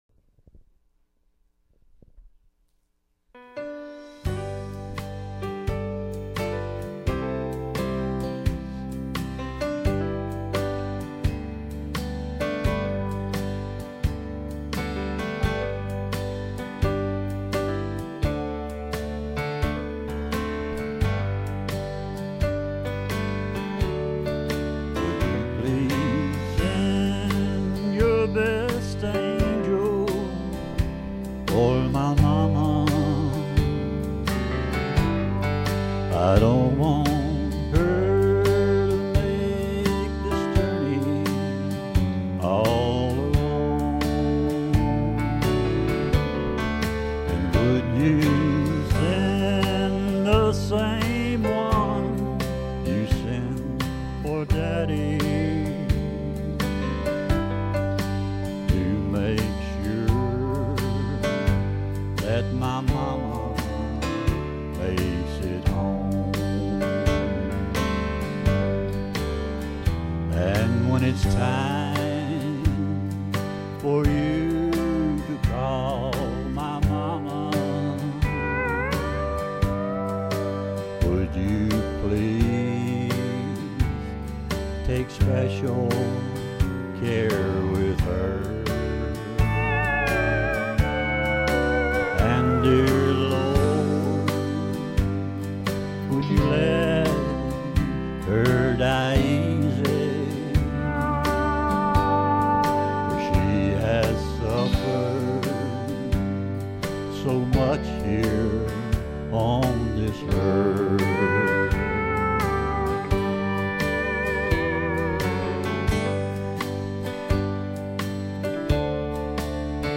2019 1 Corinthians Acts Ephesians Hebrews John Malachi Matthew Talent Temptation Testimony Time Tithe Trials truth Sermon Scripture: Acts 3:6 Download